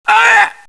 mil_pain5.wav